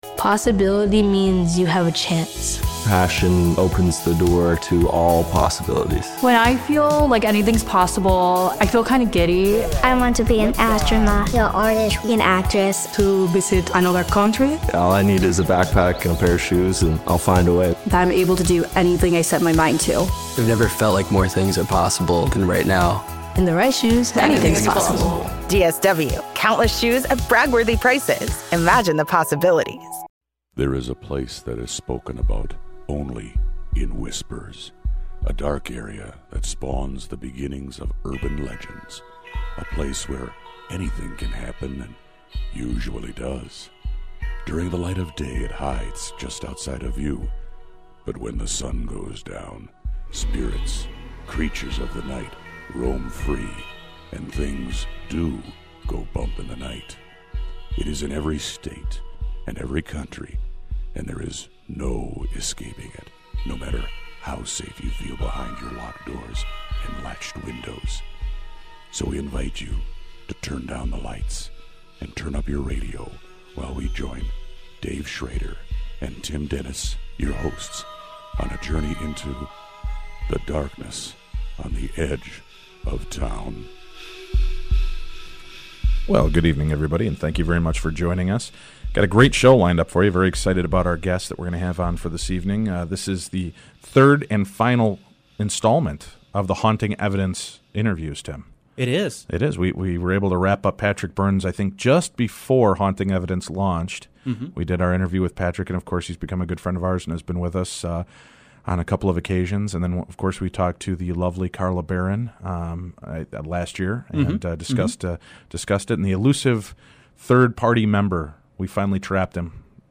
Society & Culture, Hobbies, Leisure, Religion & Spirituality, Spirituality, Philosophy